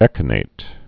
(ĕkə-nāt)